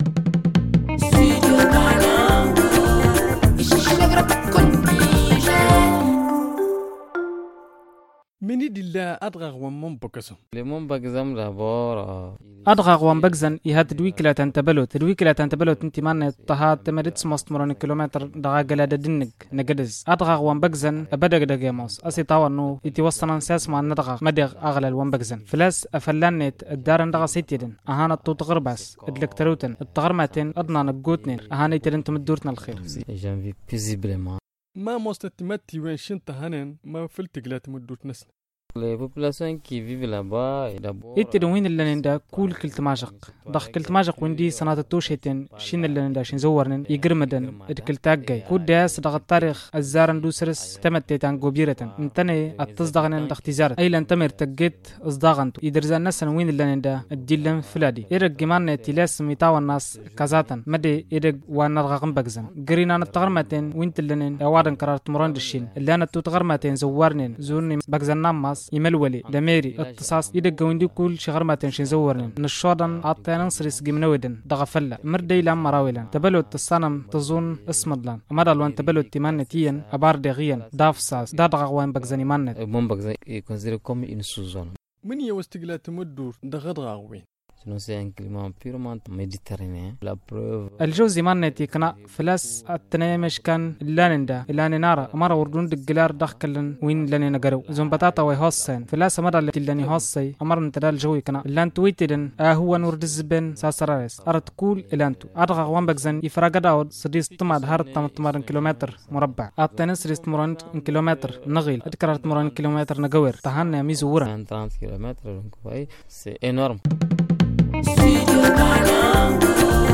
Le magazine en tamasheq